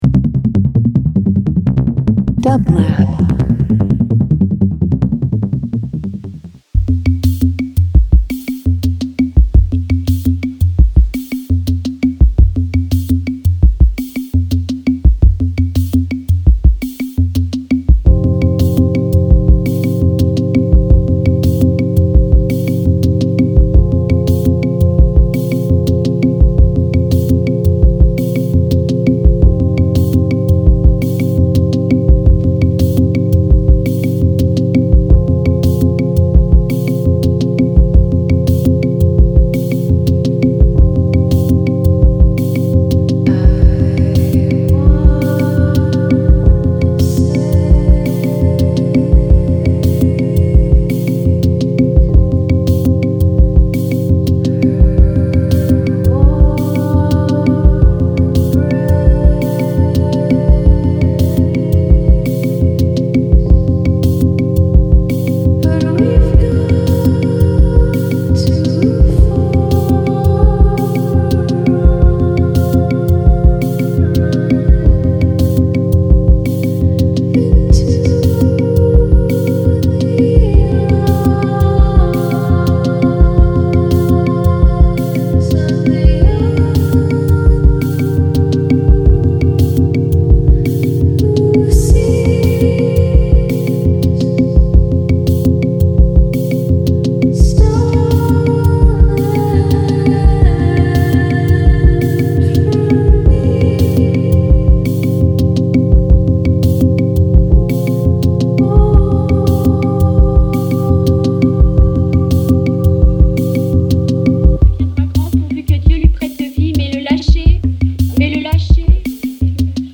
Ambient Avant-Garde Folk